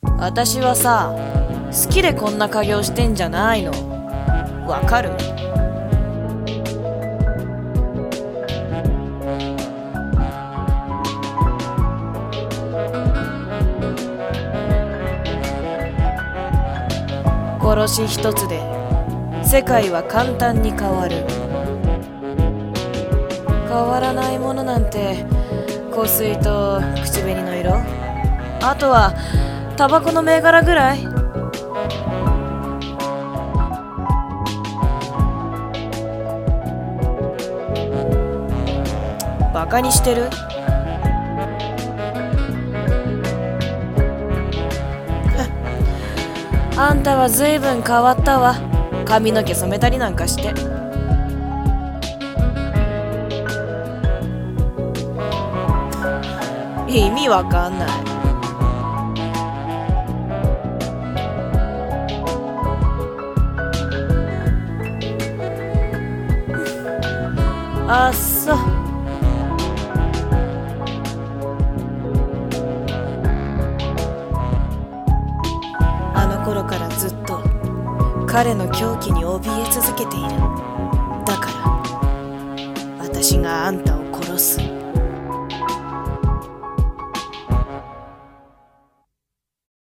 【二人声劇】Dark side